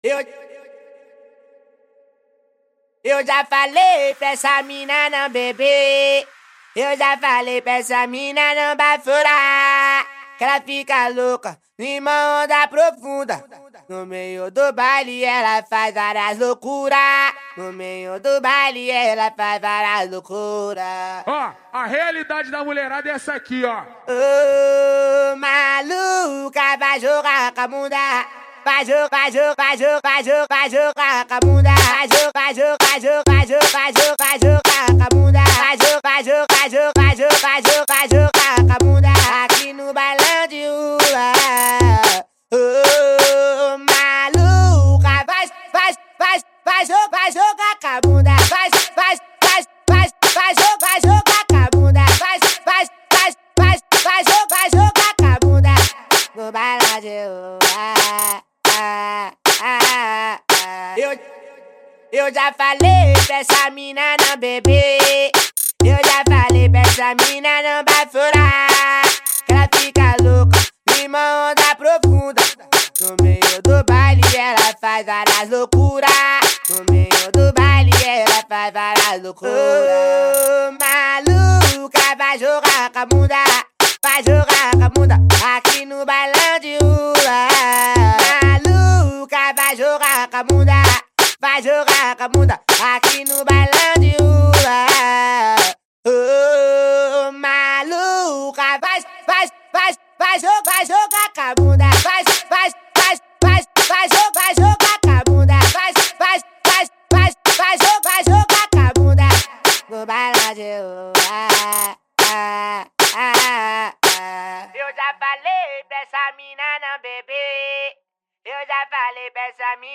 2025-01-02 20:39:32 Gênero: Funk Views